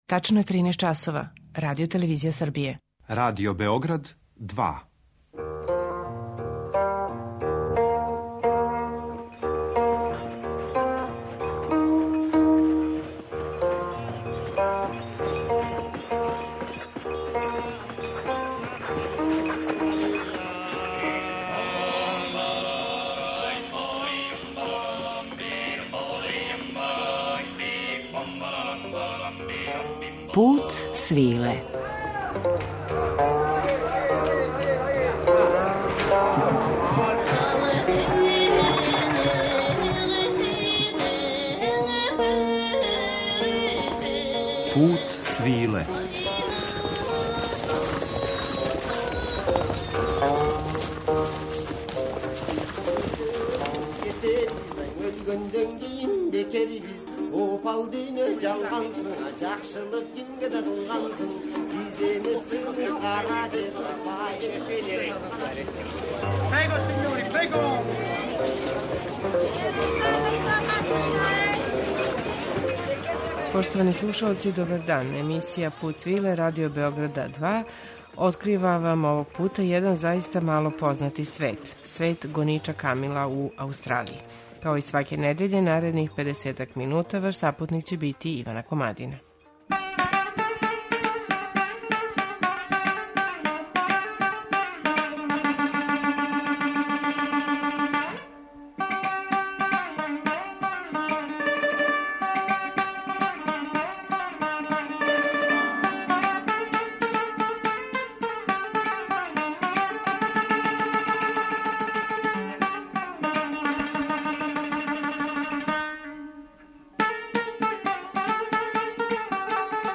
У данашњем Путу свиле истражујемо ову мало познату тему аустралијске историје. Звучно окружење пружиће нам традиционална музика из земаља из којих су Авгани доспели у Аустралију: Балучистана, Кашмира, Синда, Раџастана, Египта, Персије, Турске, Пенџаба...